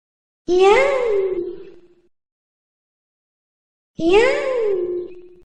sexy voice